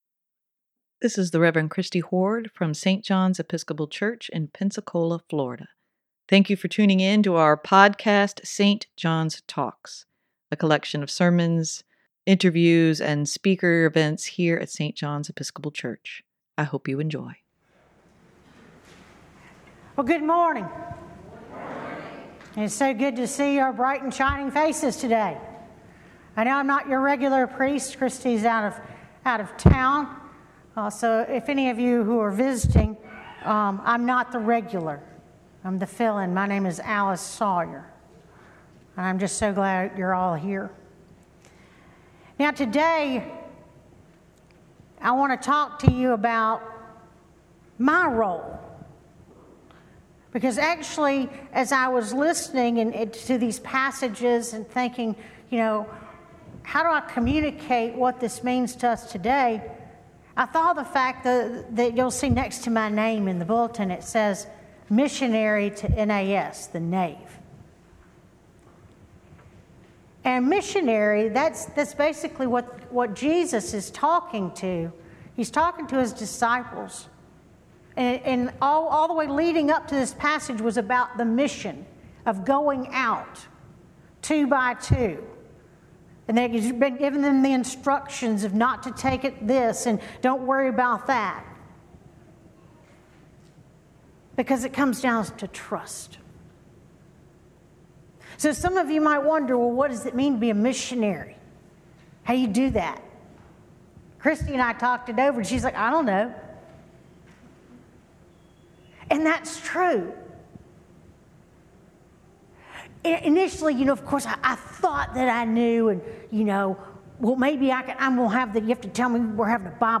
Sermon for July 2, 2023: Just try, and trust God